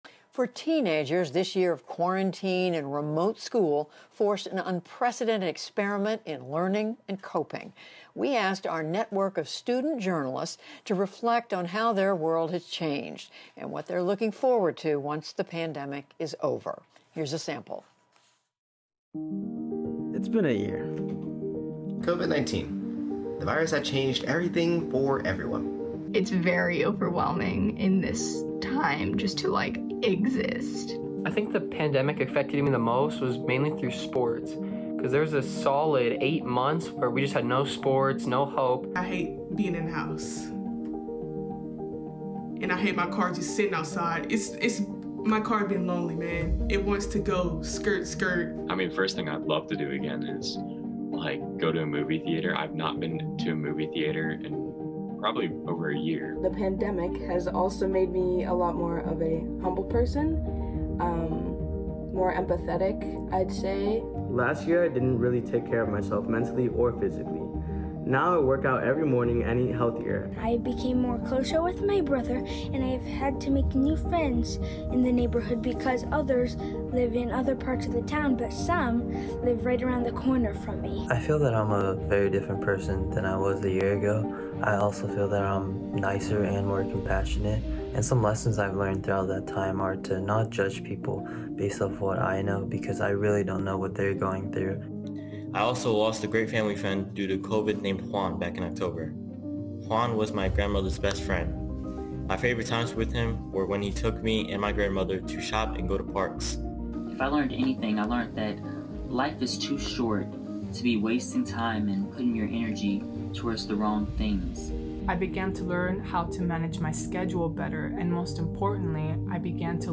英语访谈节目:青少年说"新冠如何改变我的生活"